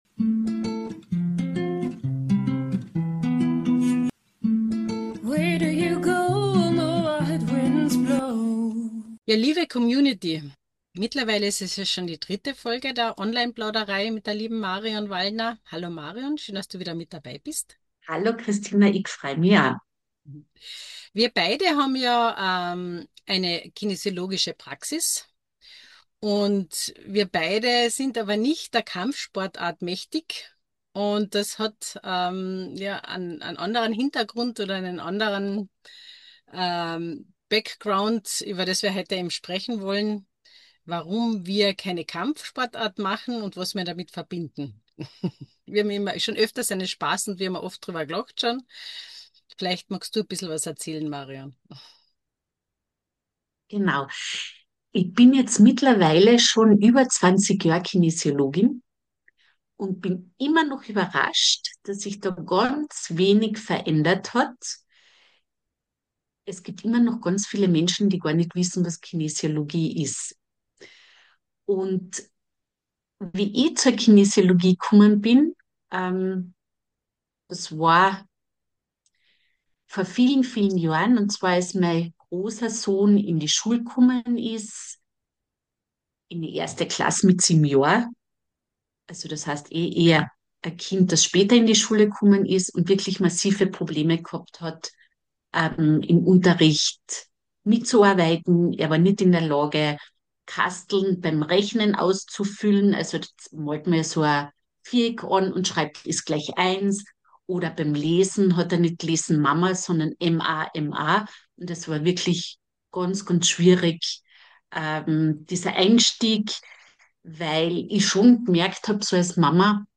Online Plauderei – inspirierende Gespräche für persönliche Entwicklung und energetische Arbeit im Online-Zeitalter.